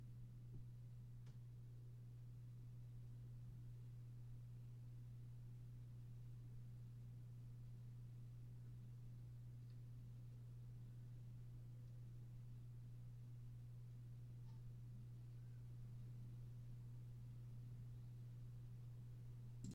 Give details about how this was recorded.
I've started recording at home, but there is noise from my apartment entering my recordings. Sample Noise Recording I just made this recording of the sound I am capturing with my mic.